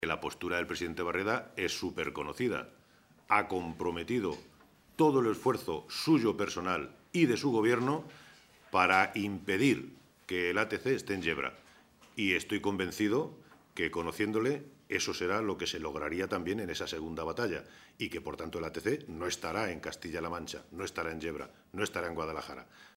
Santiago Moreno, portavoz del Grupo Parlamentario Socialista
Cortes de audio de la rueda de prensa